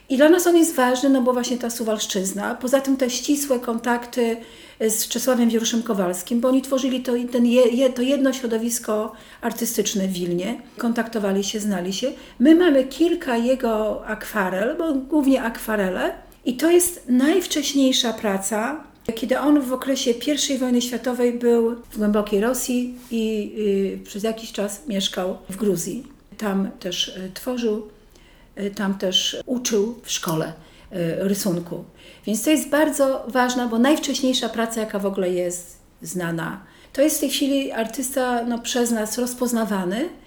07 VIII 2020; Suwałki; Muzeum Okręgowe.